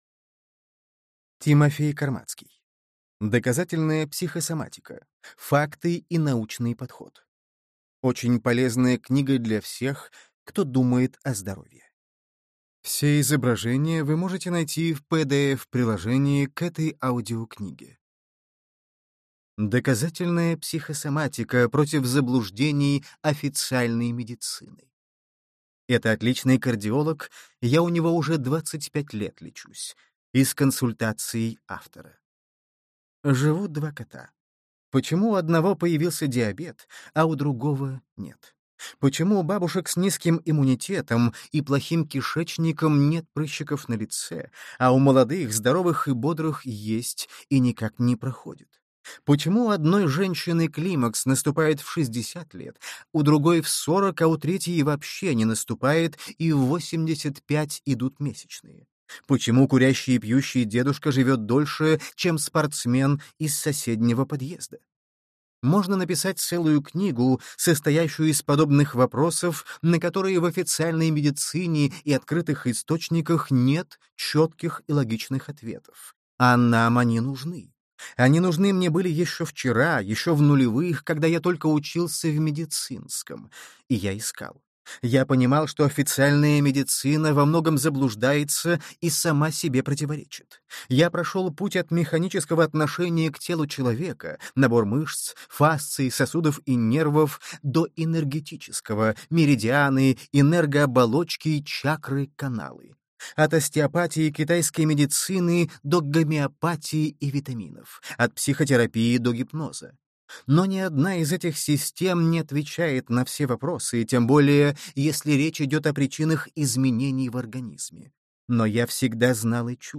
Аудиокнига Доказательная психосоматика: факты и научный подход.